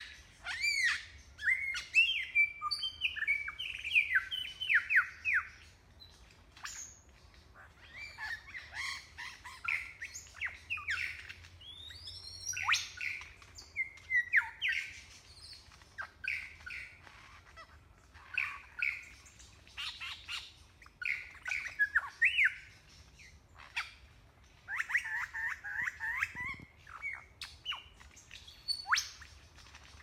Superb Lyrebird
I’m also including a clip of Lyrebird song, because the audio on the videos isn’t great; the clip will give you a better idea of how varied lyrebird songs are, and it’s much clearer.
Sound clip of Lyrebird: